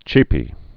(chēpē)